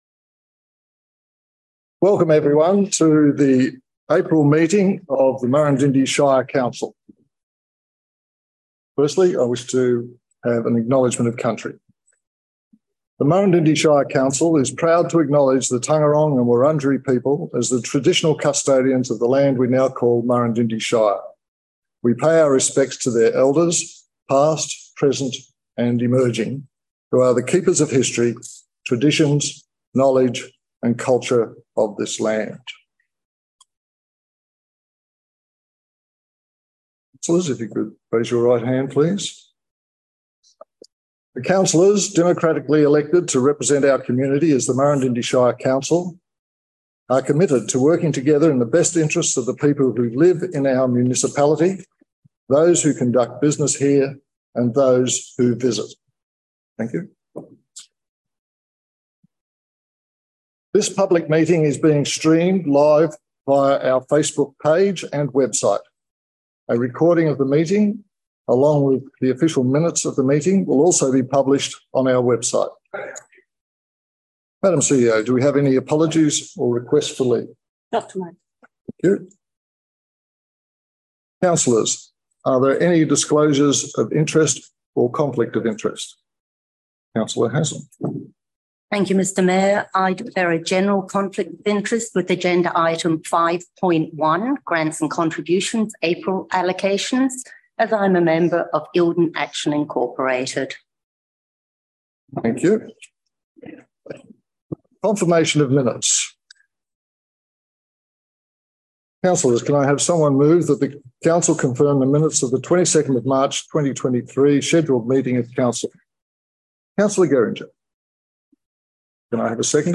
26 April 2023 Scheduled Meeting